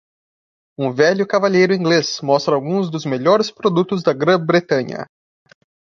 Pronunciado como (IPA)
/bɾeˈtɐ̃.ɲɐ/